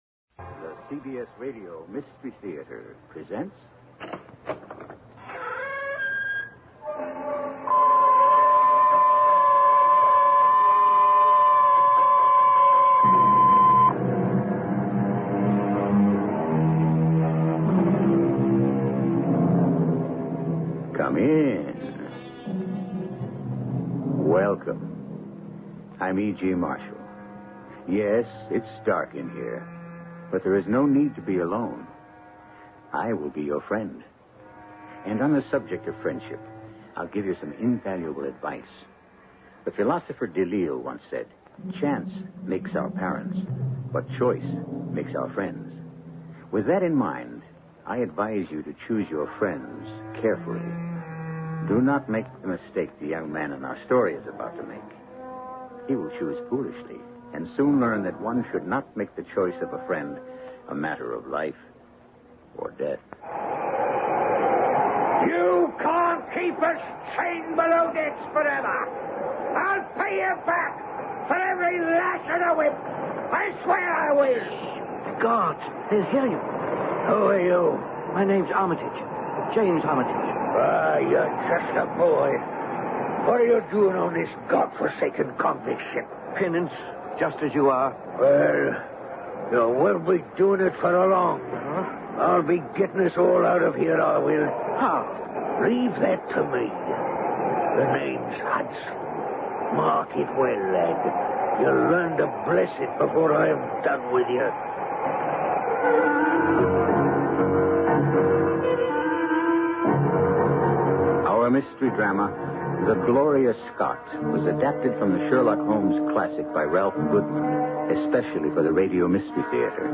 Radio Show Drama with Sherlock Holmes - The Gloria Scott 1977